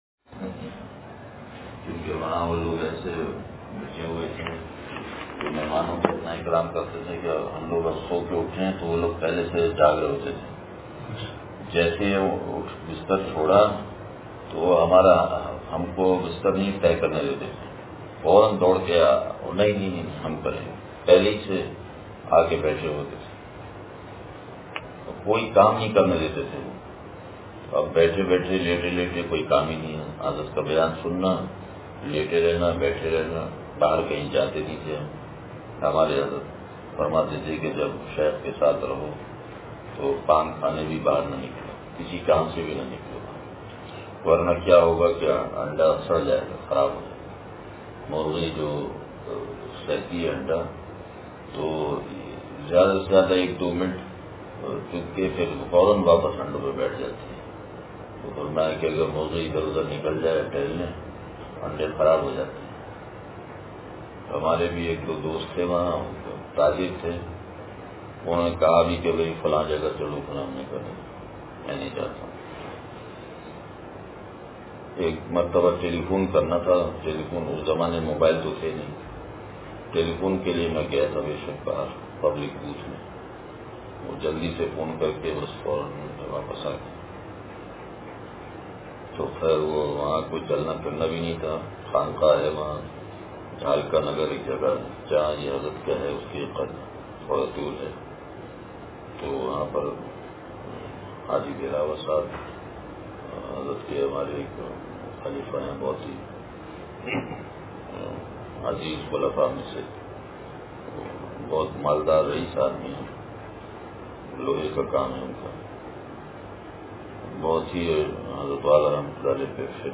اتوار مجلس